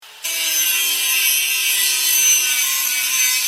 Saw Cut Sound Button - Free Download & Play